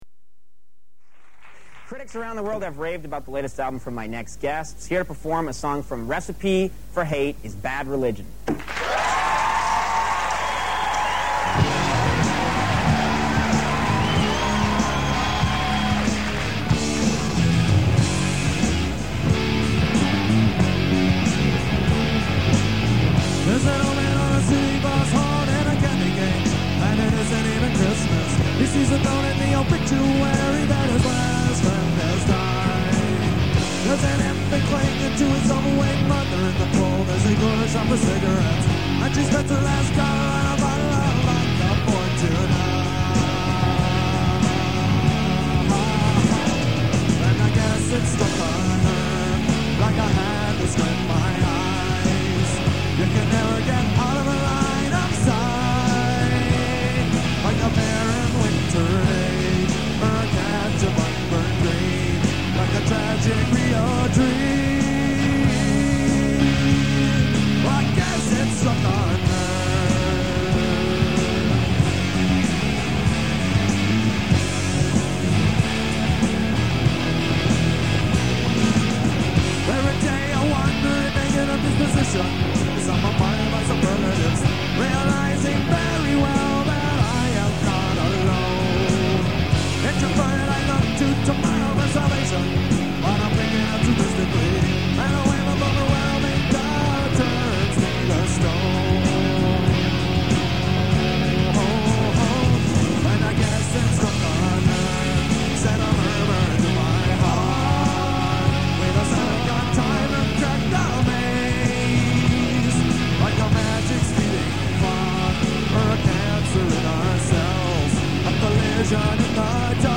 Performed live